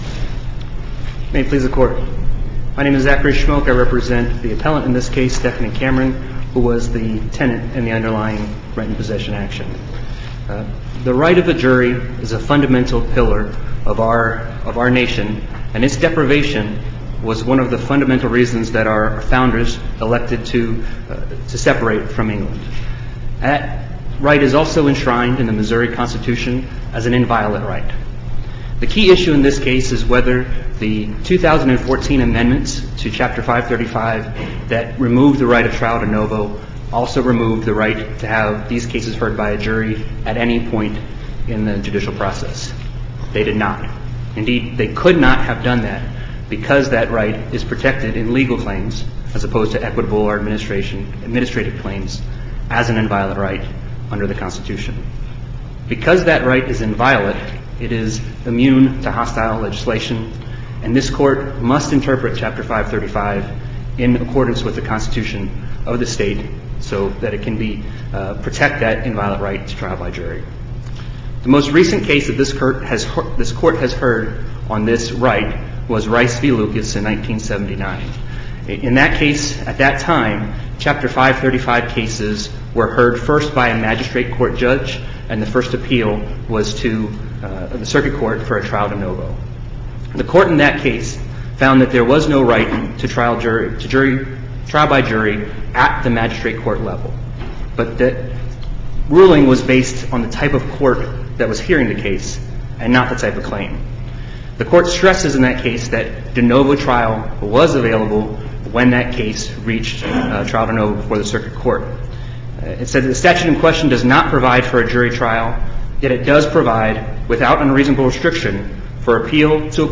MP3 audio file of arguments in SC96376
SUPREME COURT OF MISSOURI 9 a.m. Thursday, September 7, 2017